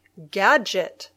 Pronunciación